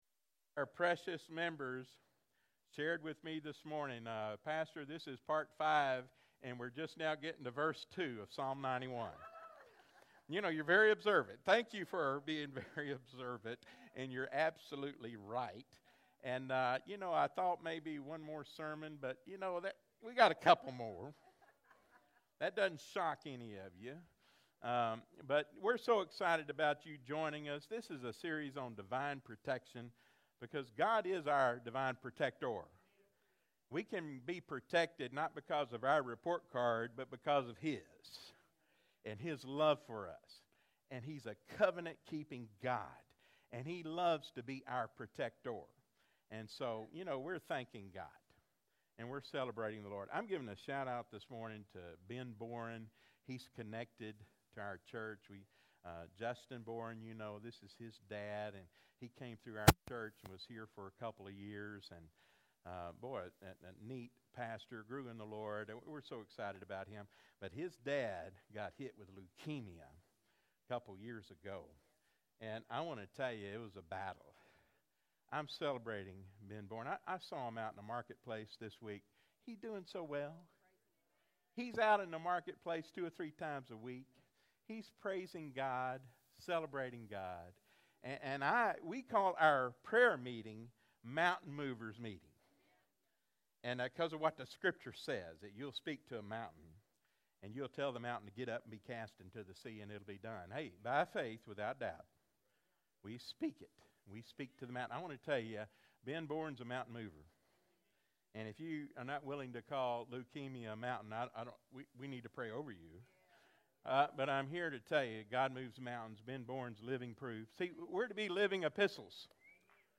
Freedom Life Fellowship Euless, TX / Freedom Life Fellowship Live Stream